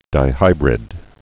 (dī-hībrĭd)